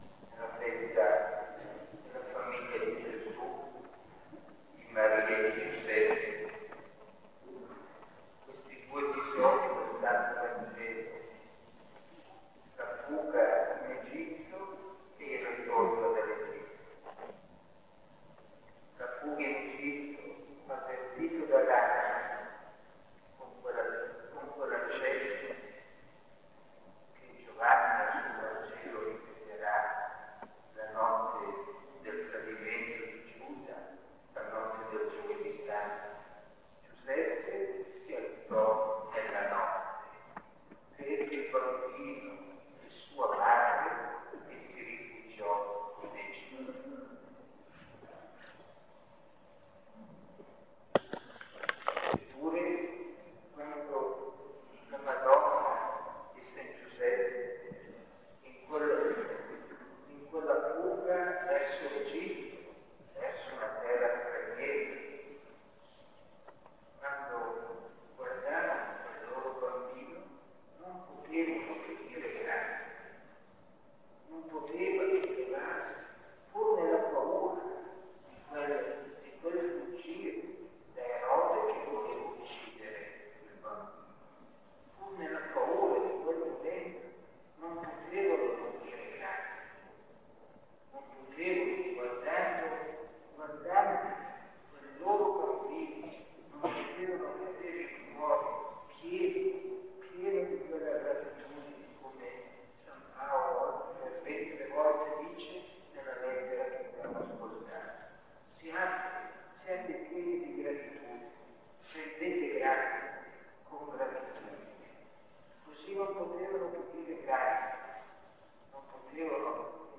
OMELIA DEL 29 DICEMBRE 2007 [Scarica]